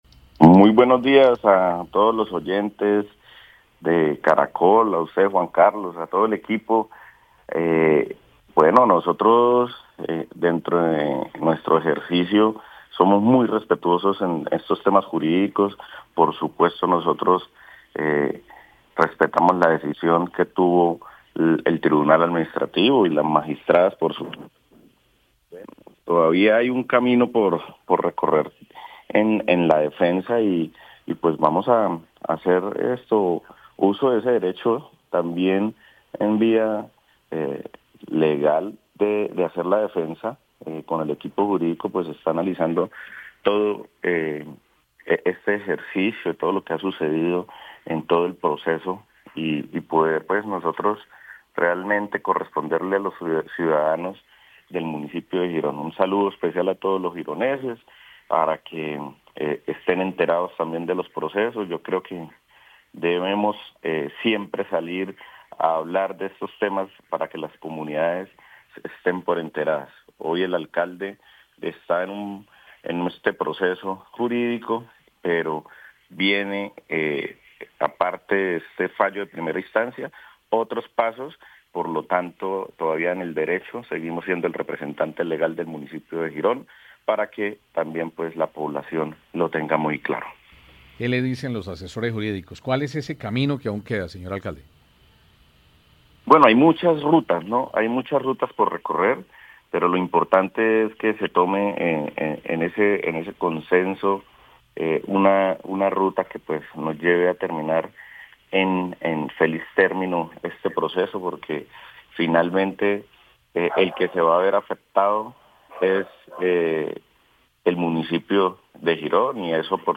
Campo Elías Ramírez, alcalde de Girón reacciona a fallo del Tribunal Administrativo de Santander